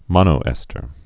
(mŏnō-ĕstər)